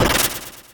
snd_rock_break.wav